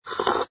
sfx_slurp_glass02.wav